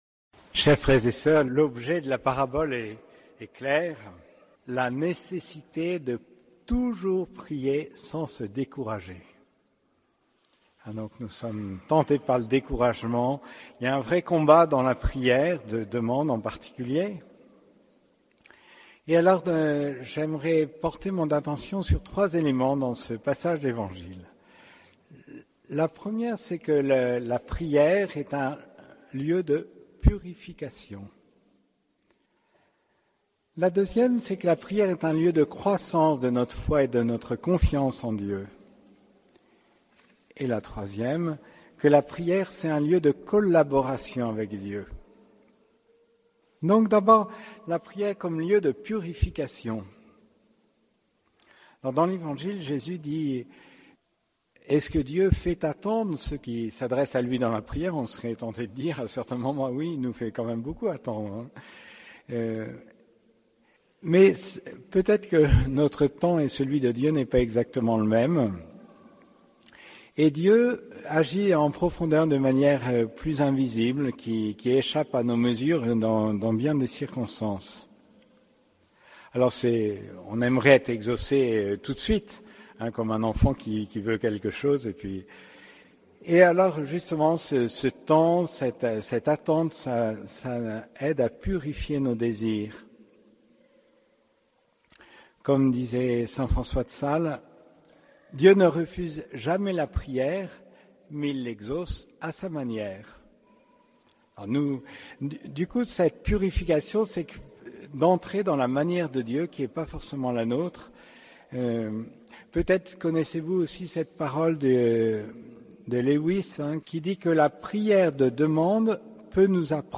Homélie du 5e dimanche du Temps Ordinaire